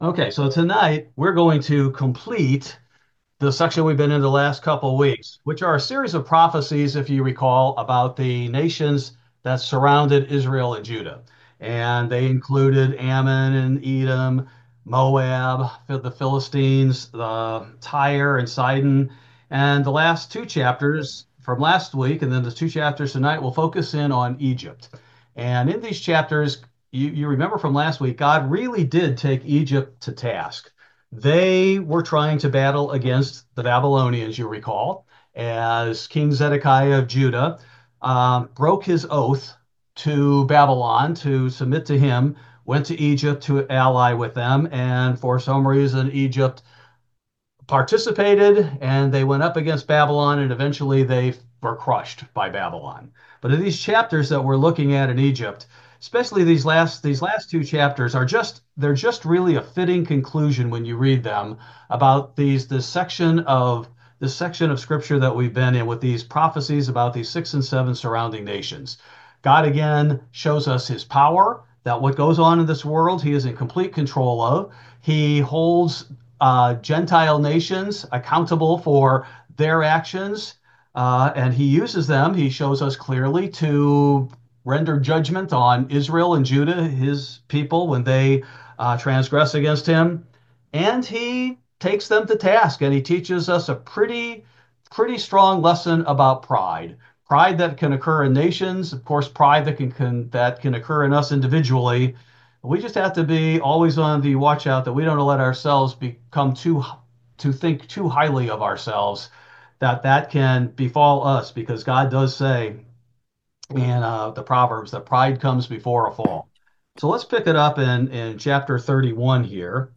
This verse by verse Bible Study focuses primarily on Ezekiel 31-32: Epitaph on Empires Fallen at the hand of God